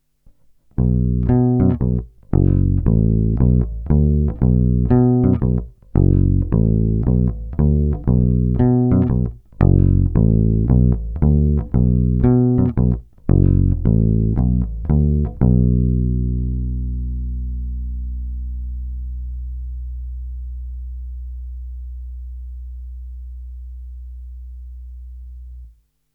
Zvuk asi mnohým sedět nebude, má výrazný kontrabasový charakter, kratší sustain, ale jinak je zamilováníhodný.
Není-li řečeno jinak, následující nahrávky jsou vyvedeny rovnou do zvukovky a kromě normalizace ponechány bez zásahů.
Hra u kobylky – zacloněno